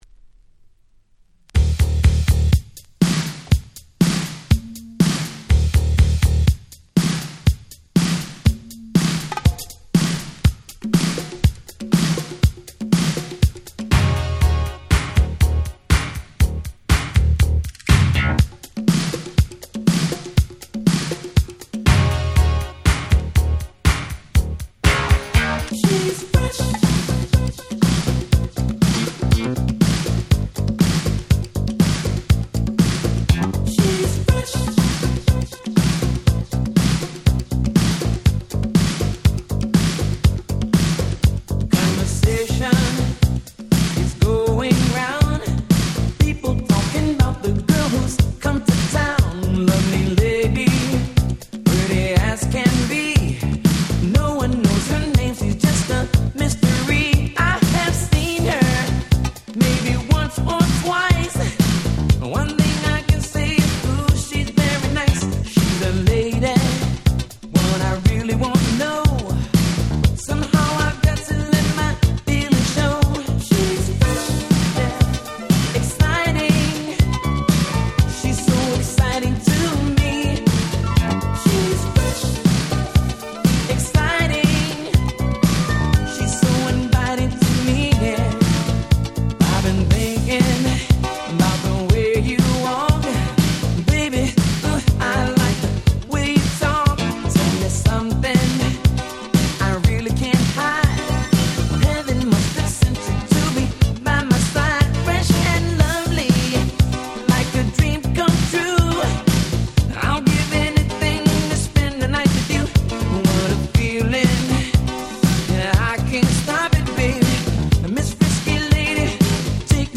84' Super Hit Soul !!